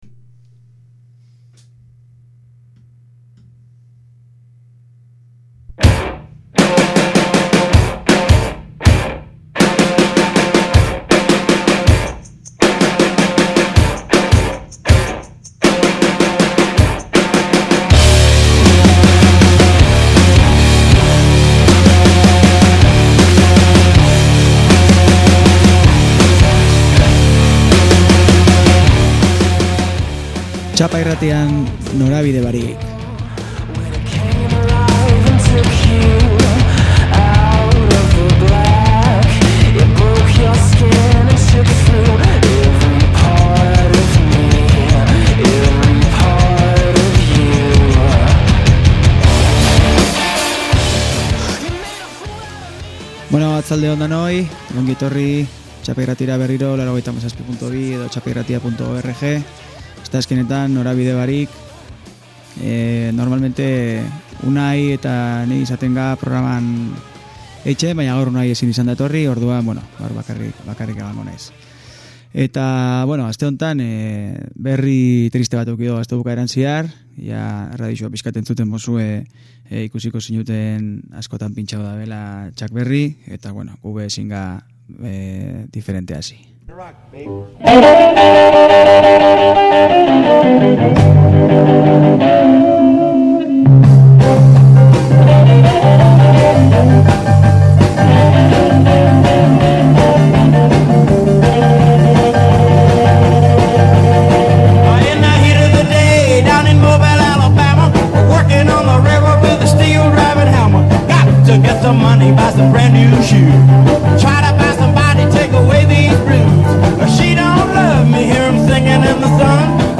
#13 – NORABIDE BARIK – Euskal Herri eta atzerriko kaña eta Rock Psychodelia!!!